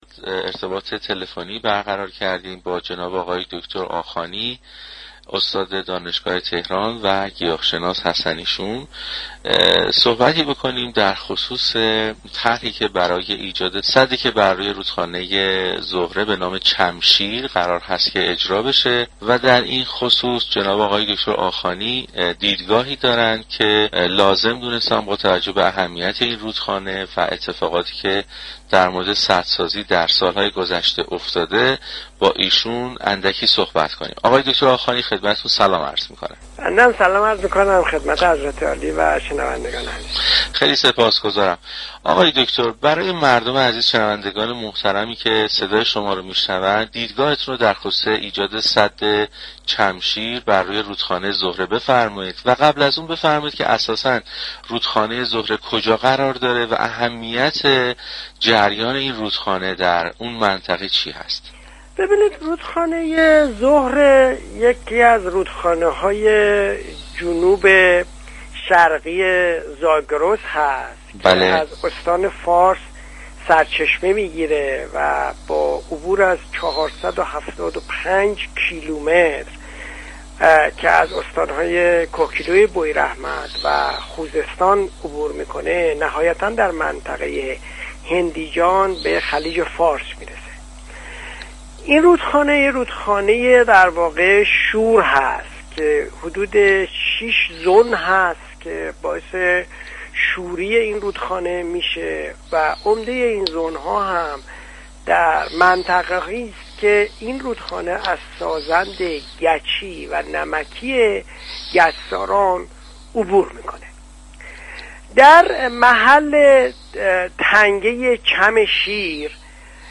در گفت و گو با پارك پردیسان رادیو تهران